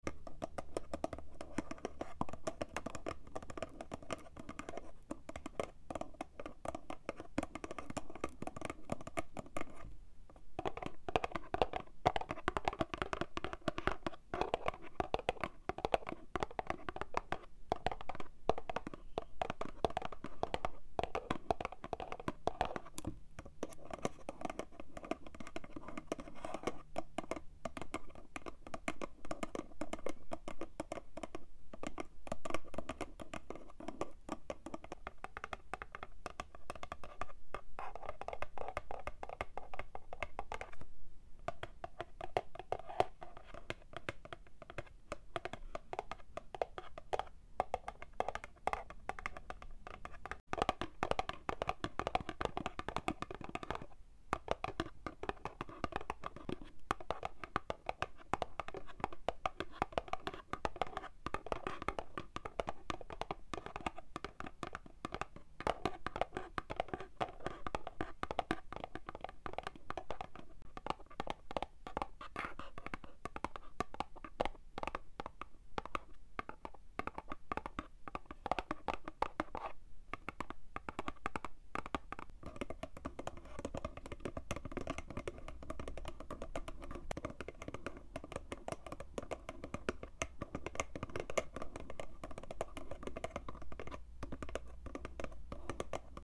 Empty box taps✨ How’re you sound effects free download